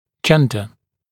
[‘ʤendə][‘джэндэ]анатомический пол